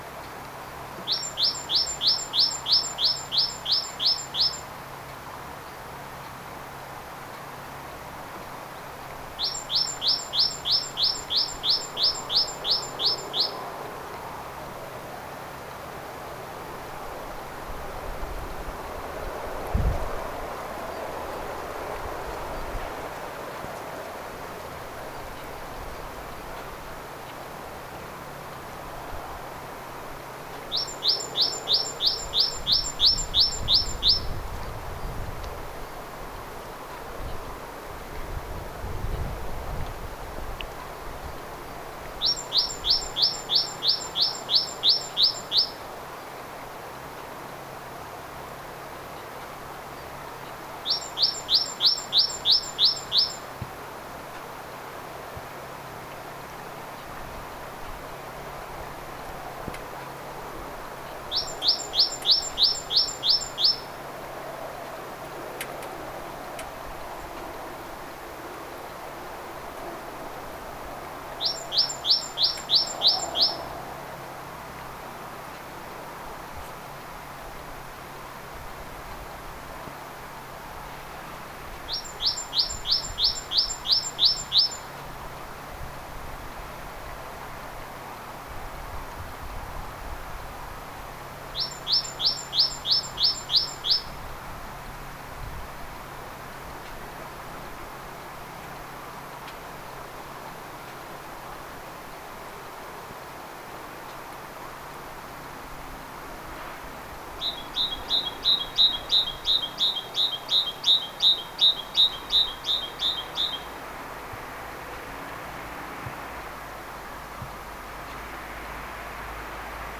talitiainen laulaa lintupöntön vierellä,
sillä on kahdenlaista laulutyyppiä
talitiainen_laulaa_ponton_vierella_silla_on_kahdenlaista_laulua.mp3